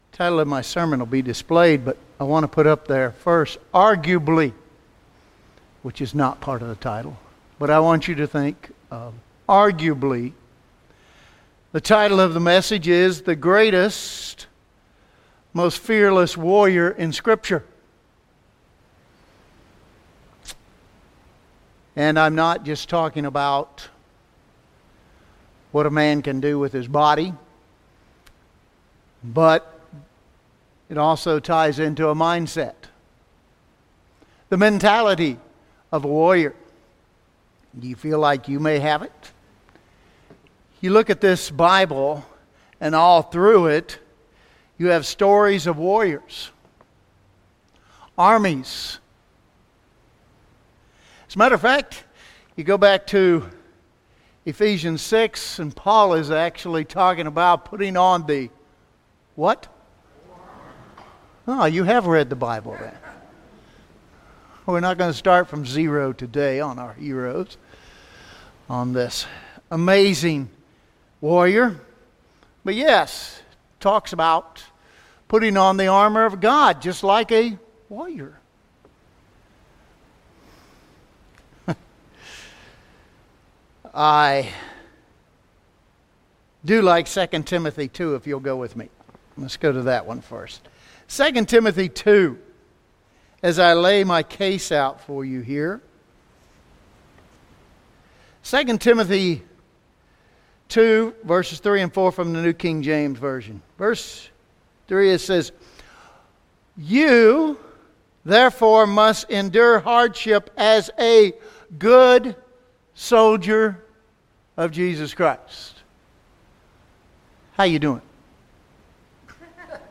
Title of my sermon will be displayed, but I want to put up there first arguably.